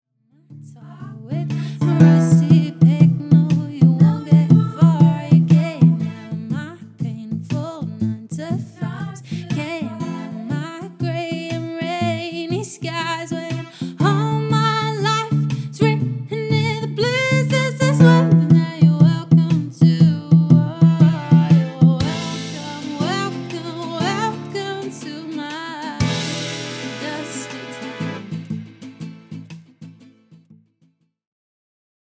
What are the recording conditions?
LIVE DEMO 5